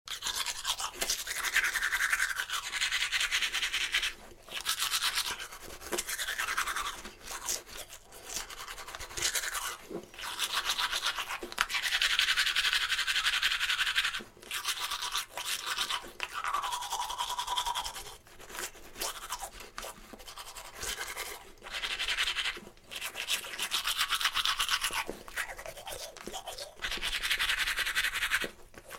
chistka-zubov_24849.mp3